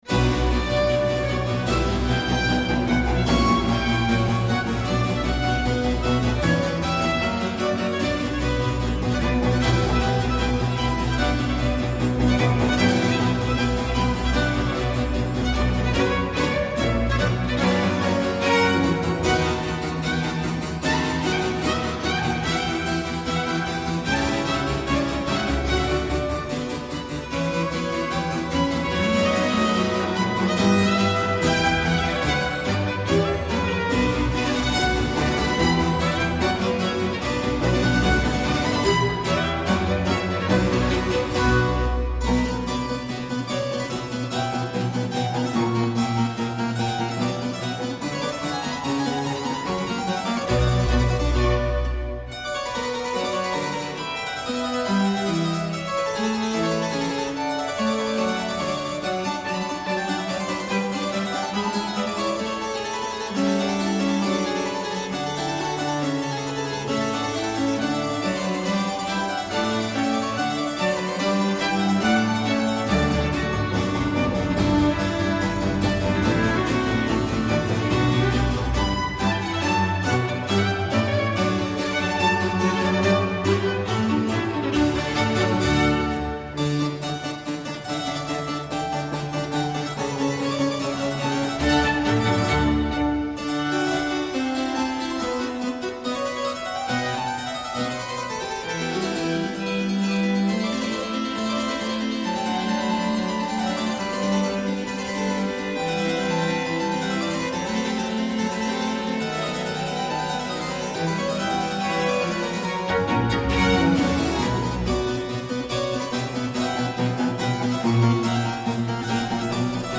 Il clavicembalo e la musica antica come compagni di viaggio.